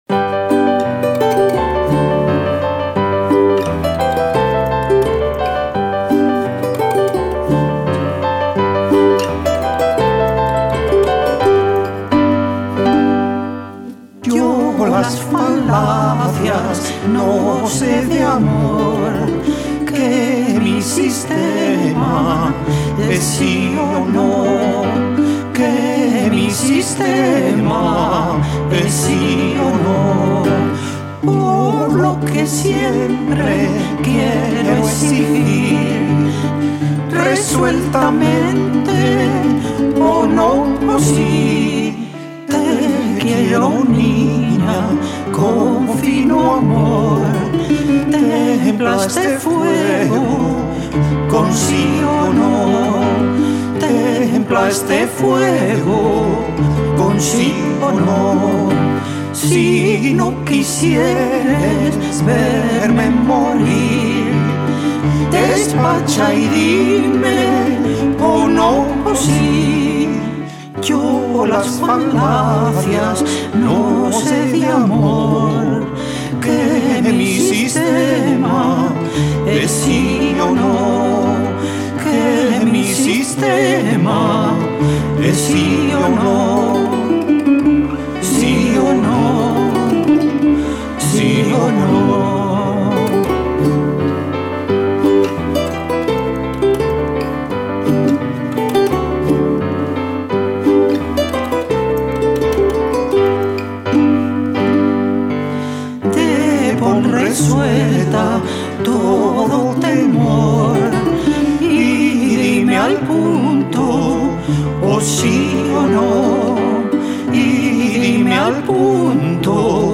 Piano
Guitarra romántica
Cello